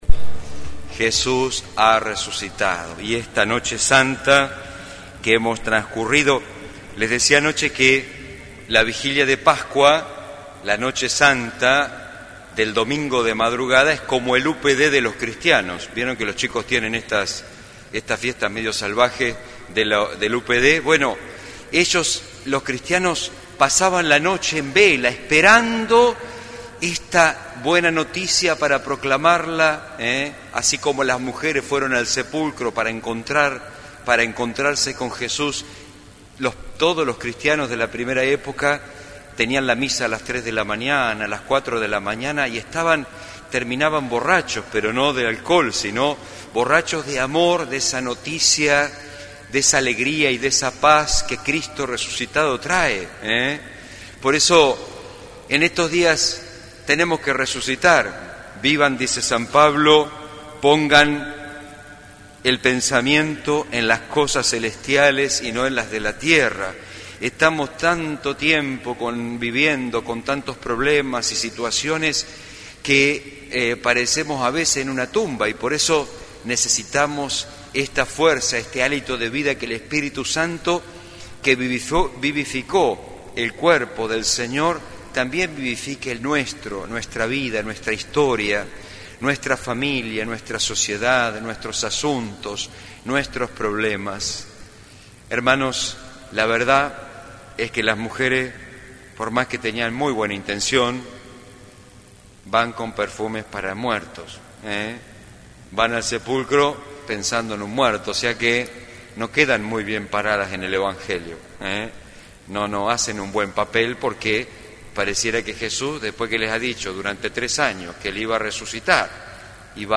El cura párroco presidió la misa del Domingo de Resurrección en el templo parroquial.